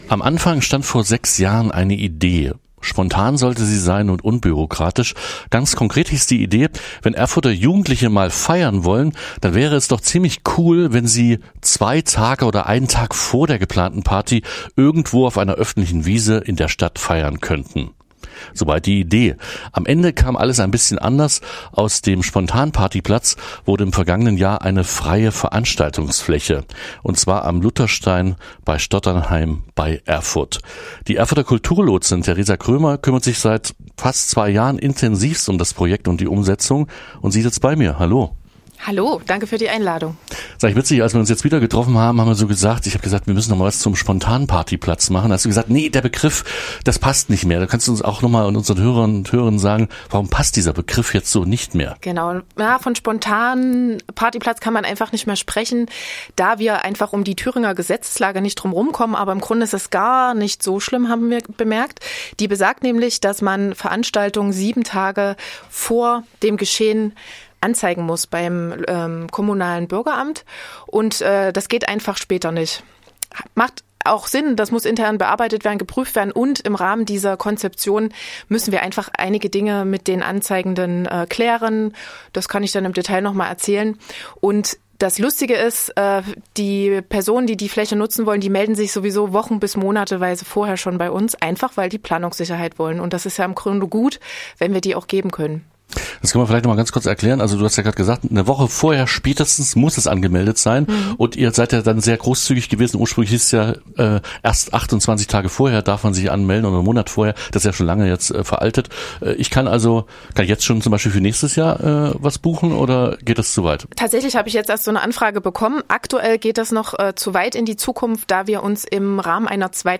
Spontan mit Plan - Gespräch